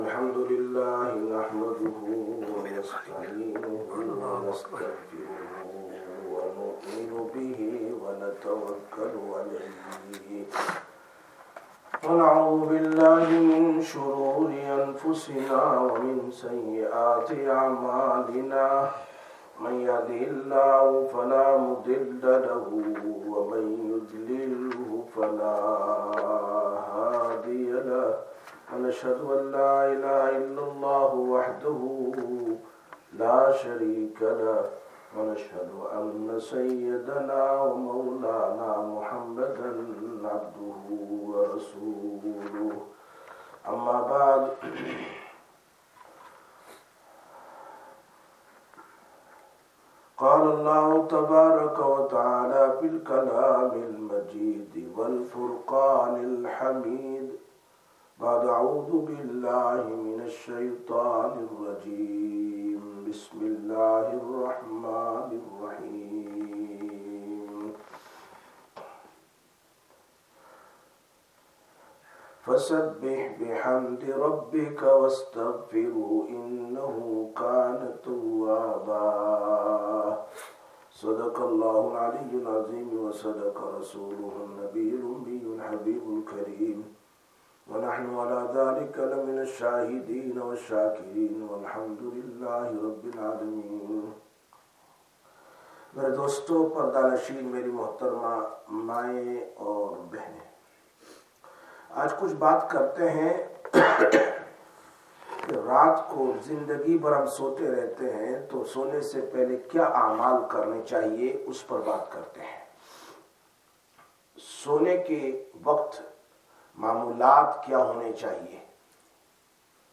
08/04/2026 Sisters Bayan, Masjid Quba